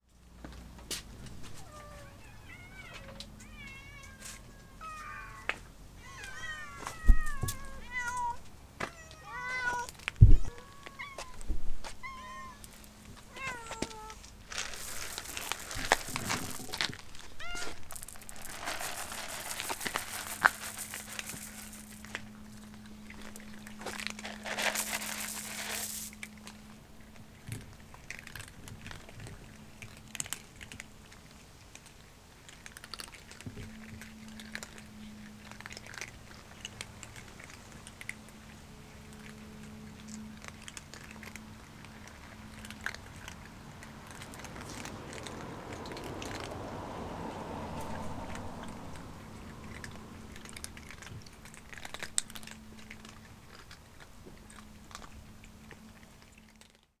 Tetrahedral Ambisonic Microphone
Credits: Eleven hungry feral cats who roam the neighborhood looking for food.